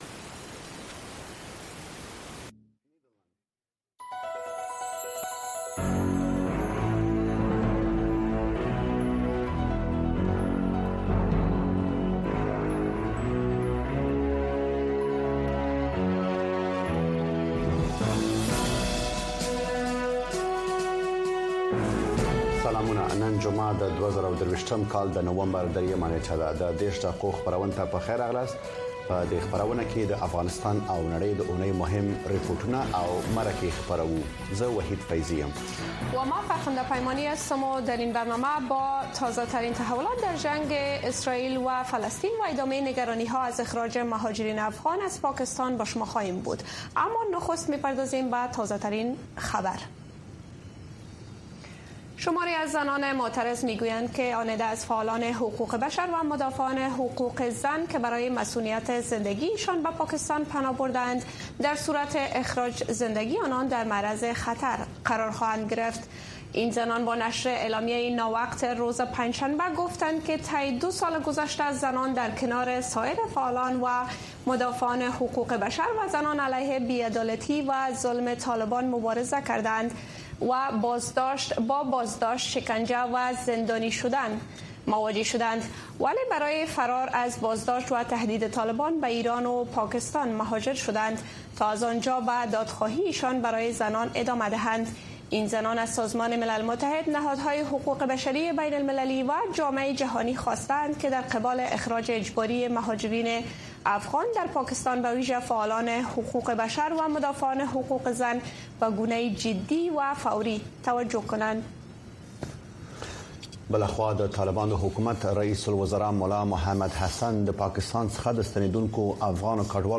د ۳۰ دقیقې په اونیزه خپرونه کې د اونۍ مهمو پیښو، رپوټونو او مرکو ته بیاکتنه کیږي او د افغانستان د ورځنیو پیښو په اړه تازه او هر اړخیرې ارزونې وړاندې کیږي. دا نیم ساعته خپرونه د هرې جمعې په ورځ د ماښام د ۷:۰۰ څخه تر ۷:۳۰ پورې د امریکاغږ د سپوږمکۍ او ډیجیټلي خپرونو له لارې خپریږي.